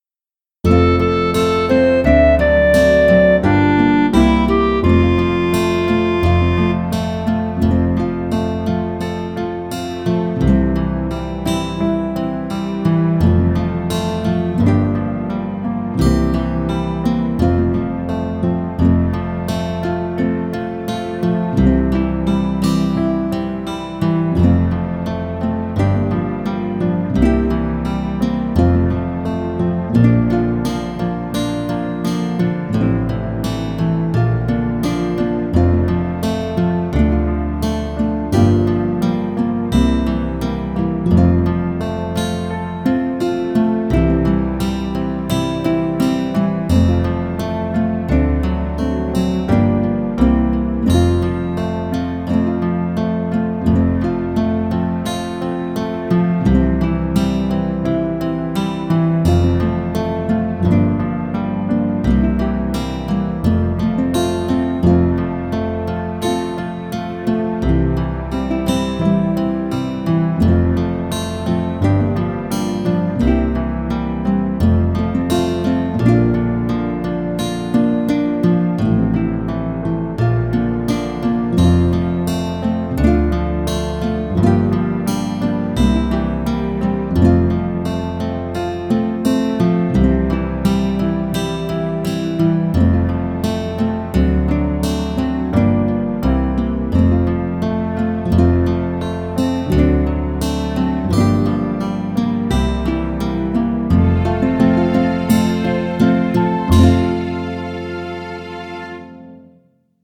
鼻笛練習用音楽素材
鼻笛教室等で使用の練習用素材はこちらにまとめます。
夏の思い出　伴奏